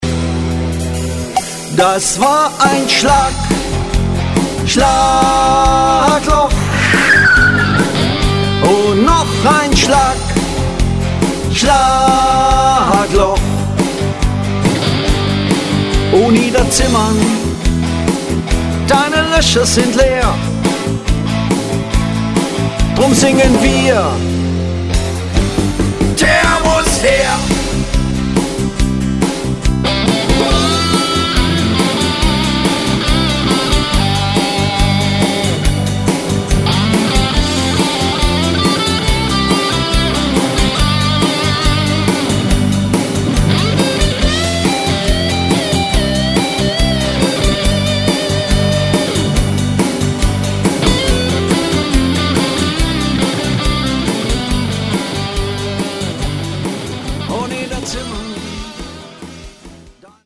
schlaglochsong.mp3